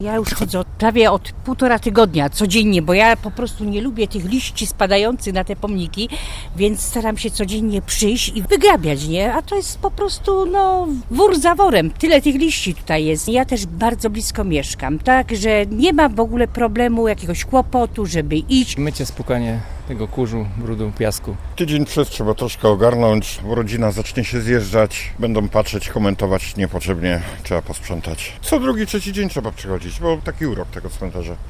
- mówili nasi rozmówcy.